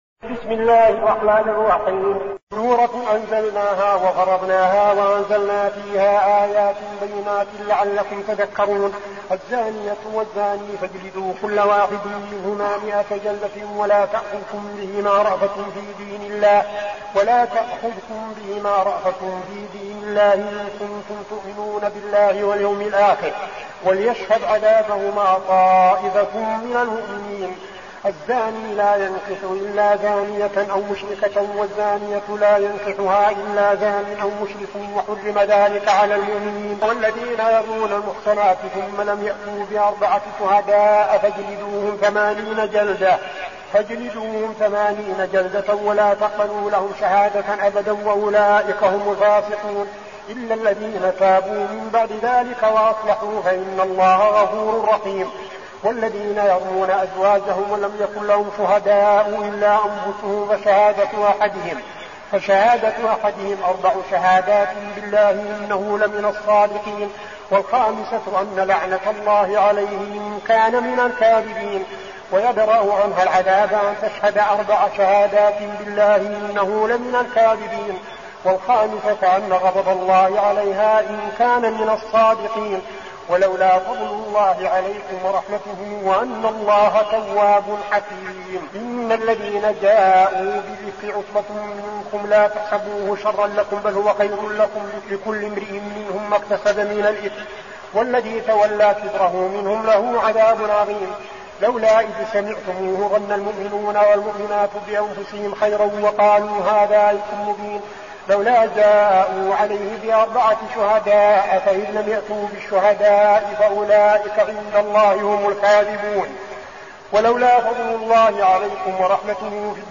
المكان: المسجد النبوي الشيخ: فضيلة الشيخ عبدالعزيز بن صالح فضيلة الشيخ عبدالعزيز بن صالح النور